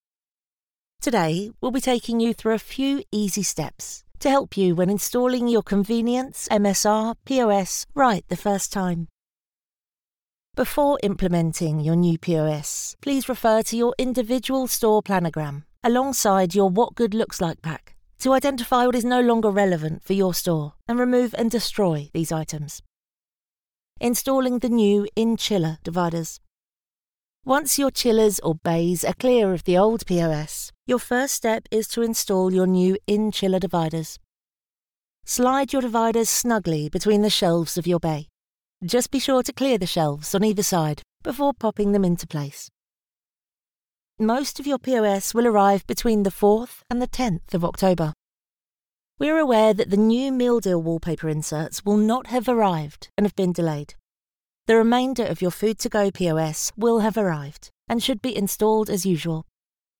Anglais (britannique)
Démo commerciale
Apprentissage en ligne